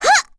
Lilia-Vox_Attack2.wav